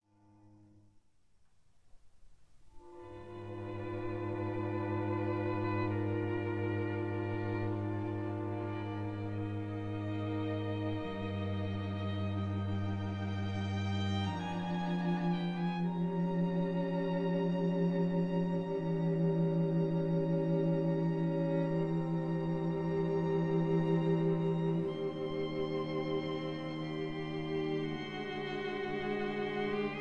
Andante con moto - Allegro vivace
in C major
violins
viola
cello